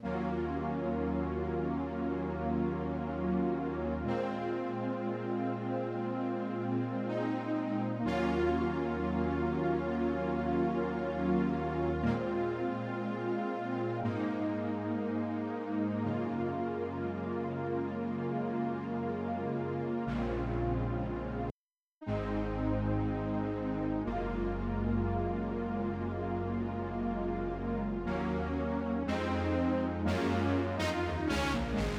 13 pad 2 B.wav